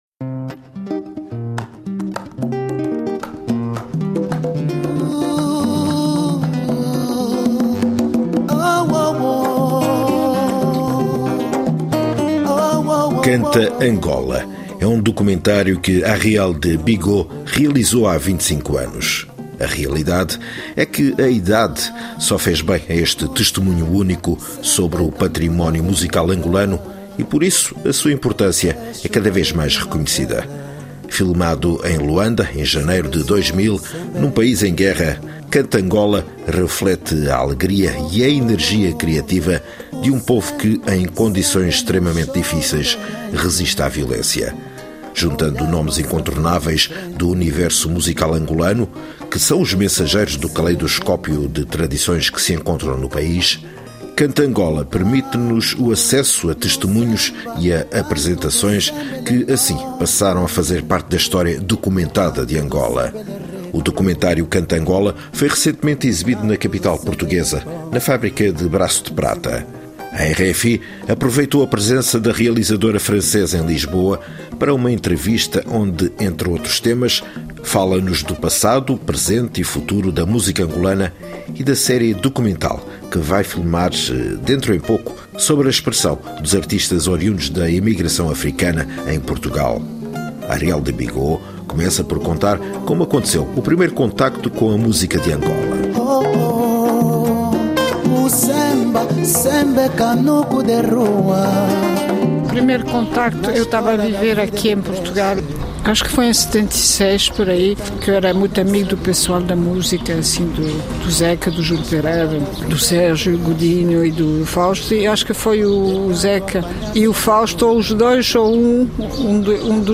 De segunda a sexta-feira (ou, quando a actualidade o justifica, mesmo ao fim de semana), sob forma de entrevista, analisamos um dos temas em destaque na actualidade.